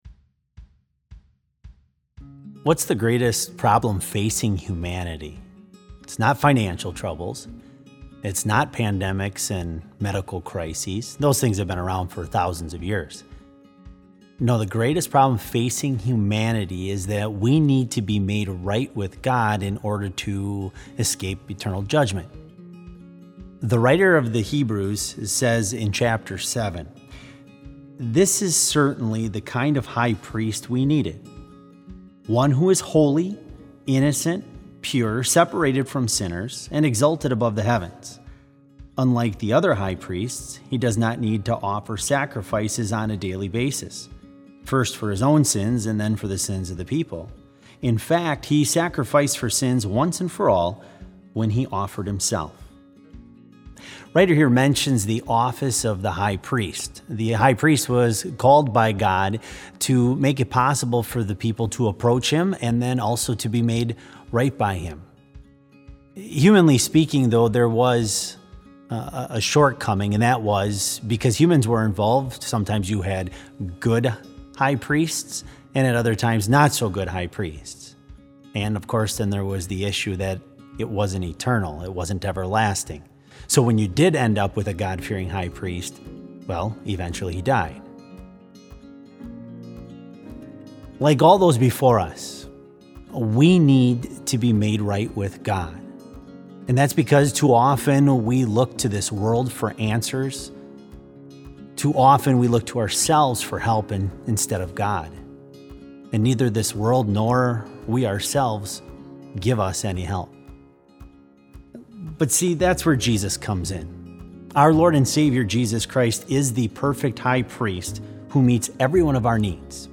Complete service audio for BLC Devotion - April 1, 2020